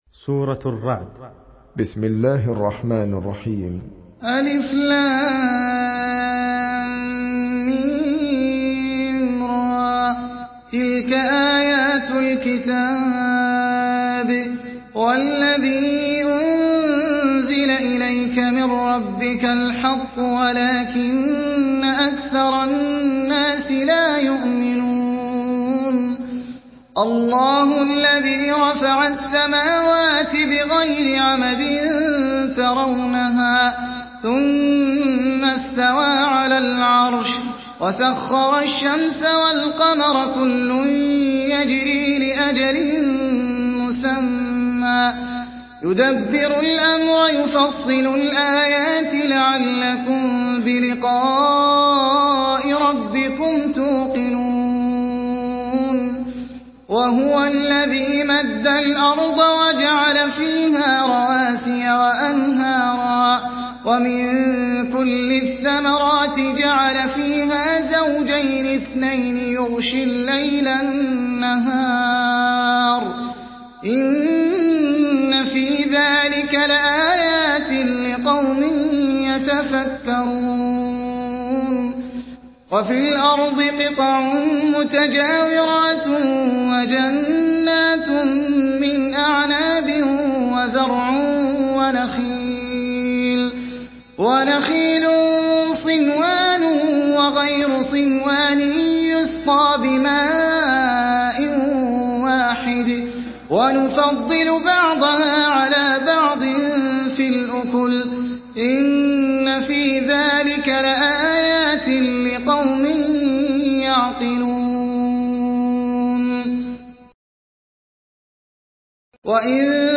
Surah আর-রা‘দ MP3 by Ahmed Al Ajmi in Hafs An Asim narration.